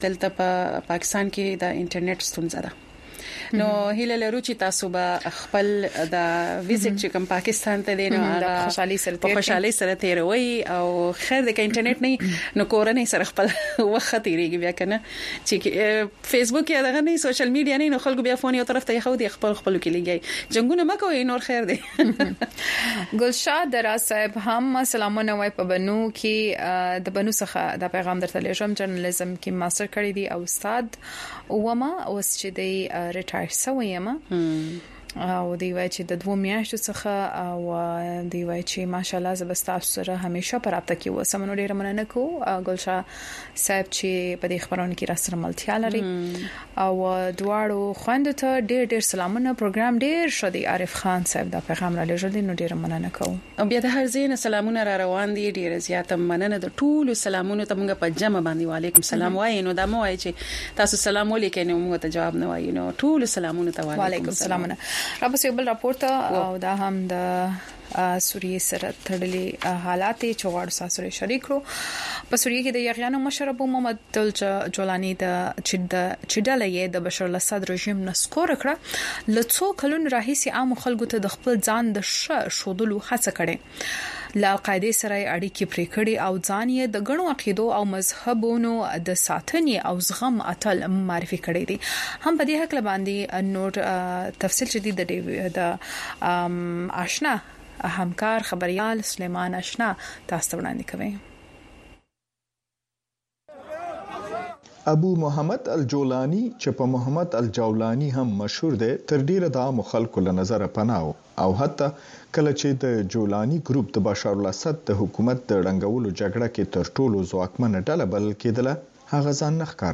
په دې دوؤ ساعتو پروگرام کې تاسو خبرونه او د هغې وروسته، په یو شمېر نړیوالو او سیمه ایزو موضوگانو د میلمنو نه پوښتنې کولی شۍ.